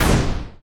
etfx_explosion_fireball.wav